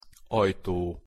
Pronunciation Hu Ajtó (audio/mpeg)